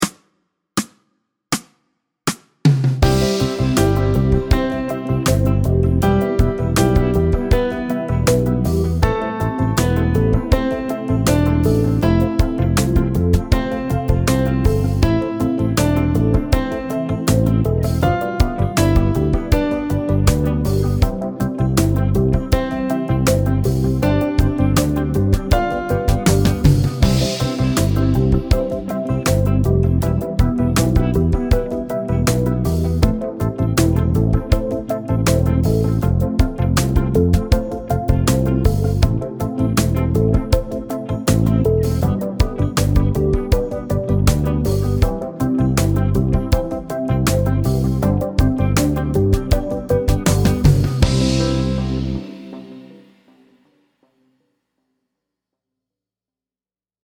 Fast C instr (demo)